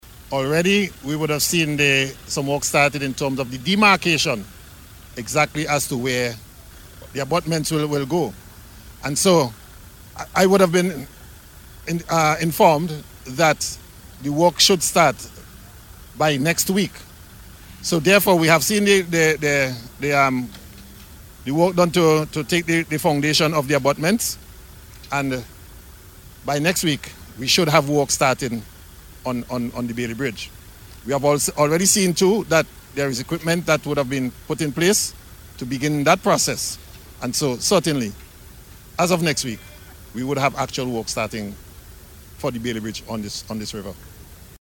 Minister Daniel made the announcement, as he spoke with the Agency for Public Information, API, during a tour of the North Windward area by members of Cabinet last week.